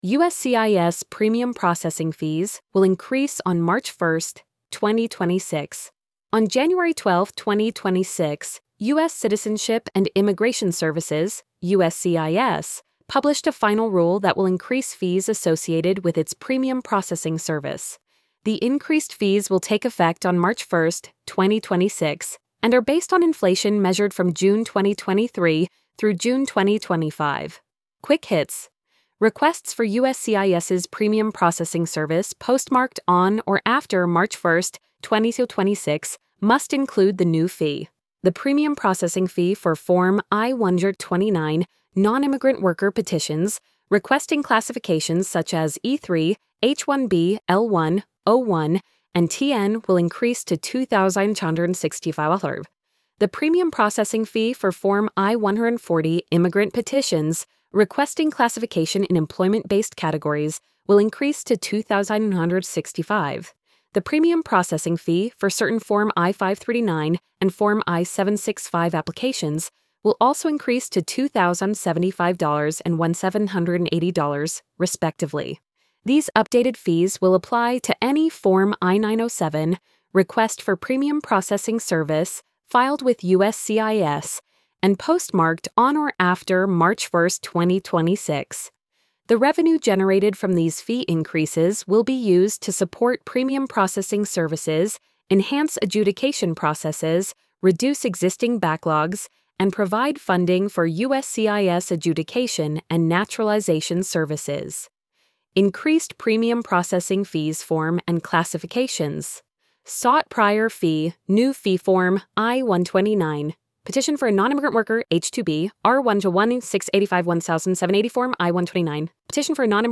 uscis-premium-processing-fees-will-increase-on-march-1-2026-tts.mp3